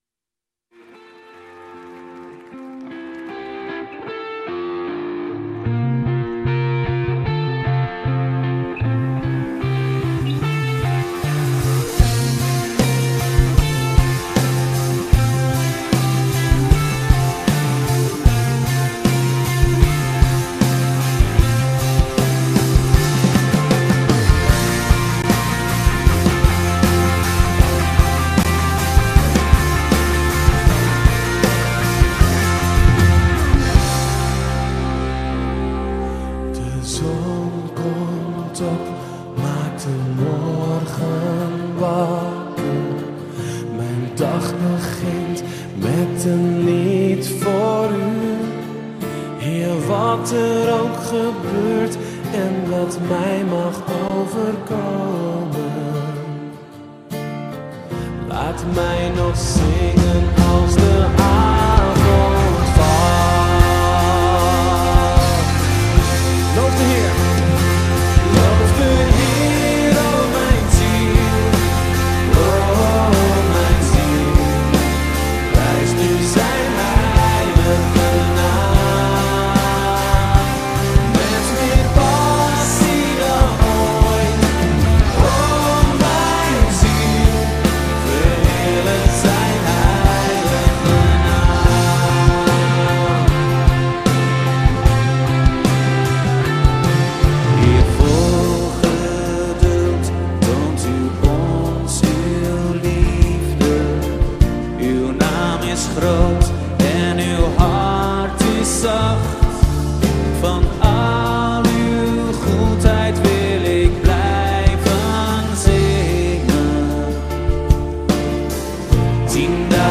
Pinksteren